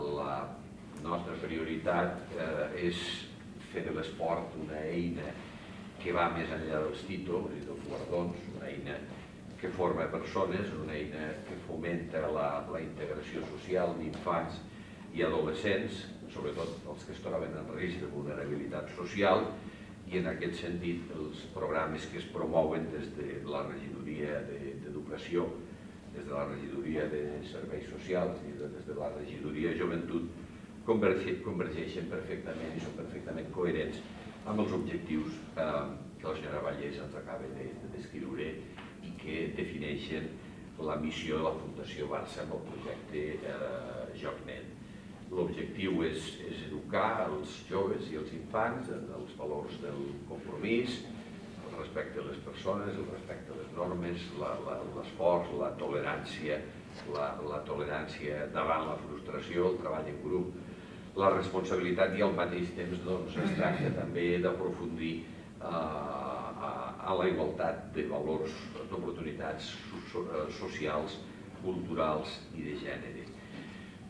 tall-de-veu-del-paer-en-cap-de-lleida-miquel-pueyo-amb-motiu-de-la-signatura-del-conveni-del-programa-futbolnet